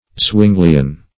Meaning of zwinglian. zwinglian synonyms, pronunciation, spelling and more from Free Dictionary.
Search Result for " zwinglian" : The Collaborative International Dictionary of English v.0.48: Zwinglian \Zwing"li*an\, prop. a. (Theol.)